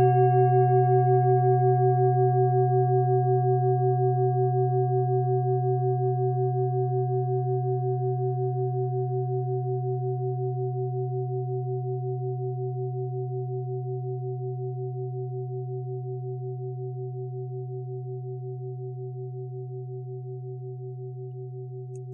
Tibet Klangschale Nr.40, Planetentonschale: Meisterton
Klangschalen-Gewicht: 1900g
Klangschalen-Durchmesser: 25,7cm
(Ermittelt mit dem Filzklöppel oder Gummikernschlegel)
Die Klangschale hat bei 127.44 Hz einen Teilton mit einer
Die Klangschale hat bei 129.15 Hz einen Teilton mit einer
Die Klangschale hat bei 375.97 Hz einen Teilton mit einer
Die Klangschale hat bei 381.59 Hz einen Teilton mit einer
klangschale-tibet-40.wav